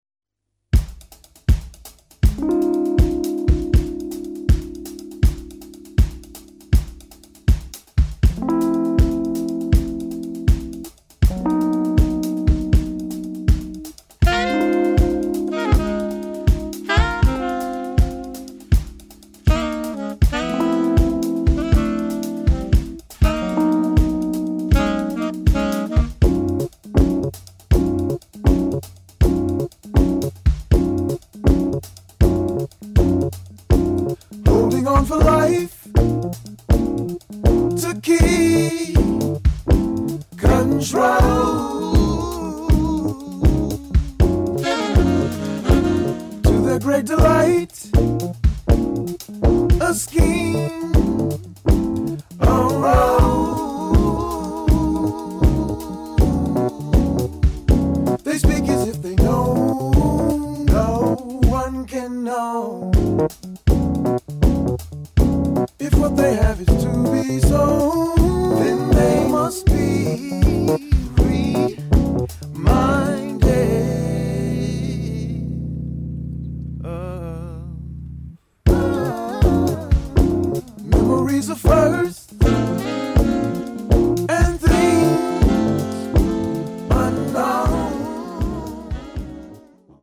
[ JAZZ / SOUL ]